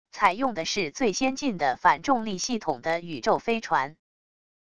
采用的是最先进的反重力系统的宇宙飞船wav音频